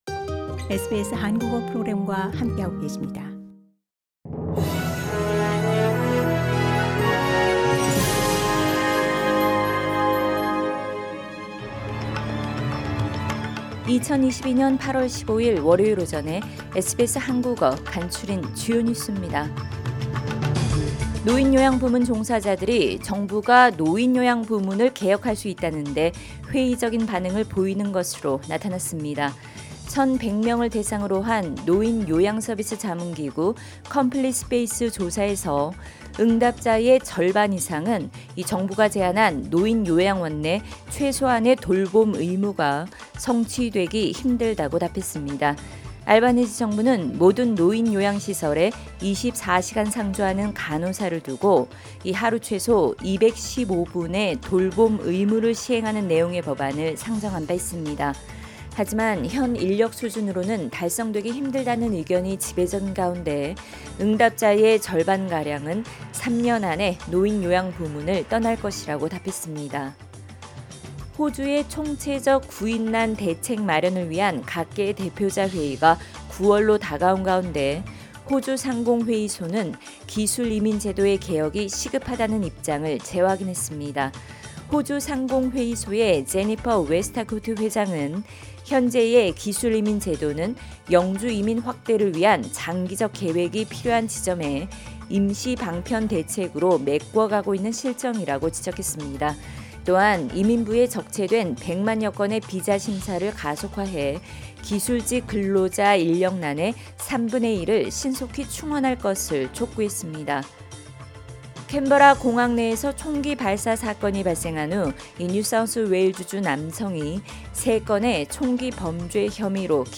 2022년 8월 15일 월요일 아침 SBS 한국어 간추린 주요 뉴스입니다.